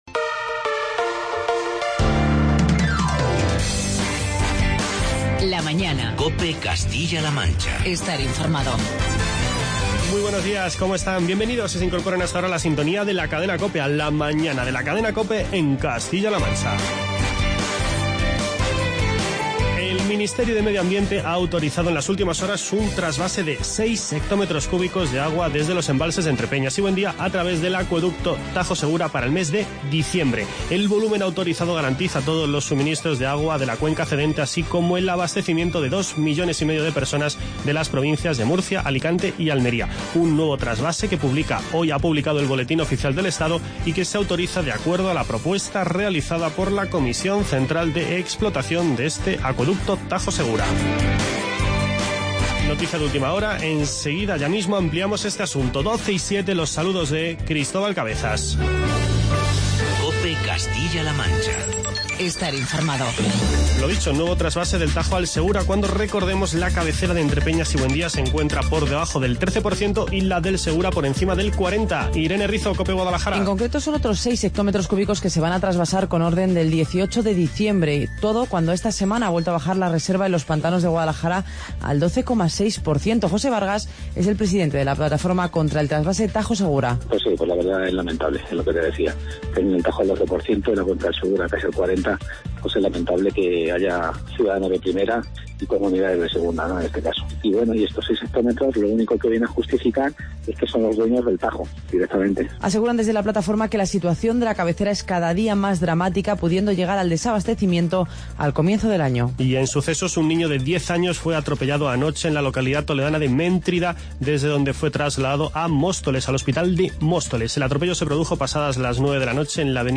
Charlamos con Rosa Melchor, alcaldesa de Alcázar de San Juan